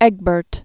(ĕgbərt) Died 839.